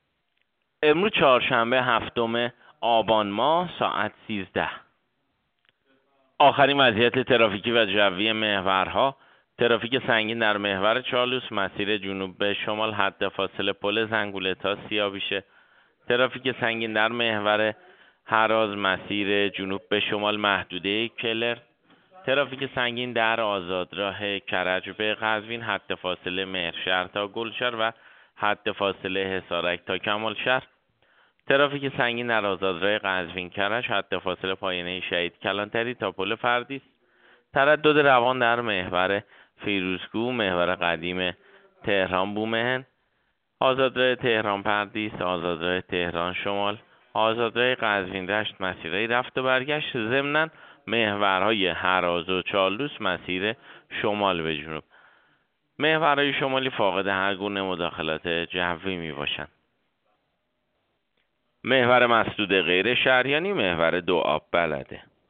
گزارش رادیو اینترنتی از آخرین وضعیت ترافیکی جاده‌ها ساعت ۱۳ هفتم آبان؛